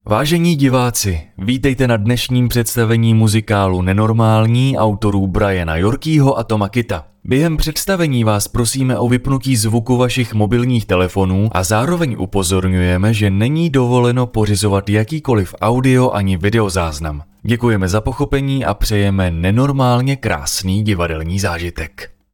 VOICE OVER / KOMENTÁŘ / DABING / ZPĚV
2. HLÁŠENÍ-divadlo.mp3